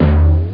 1 channel
snd_128_FileDone.mp3